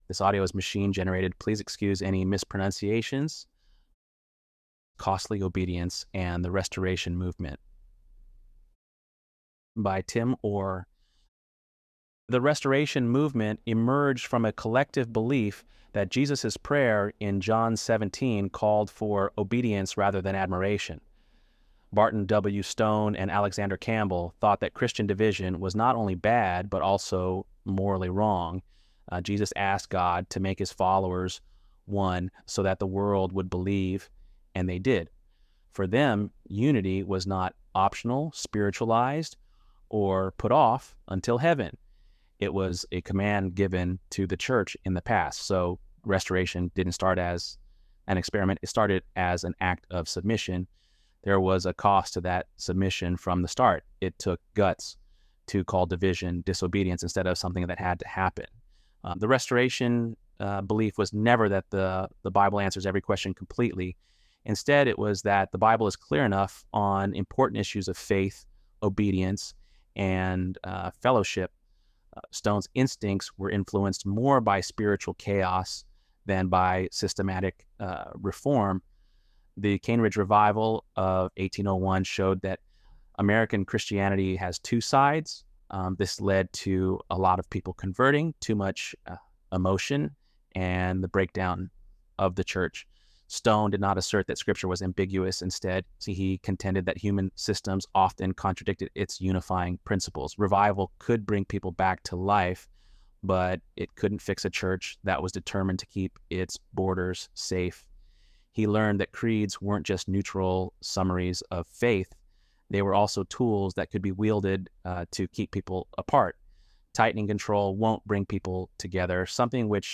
ElevenLabs_1_28.mp3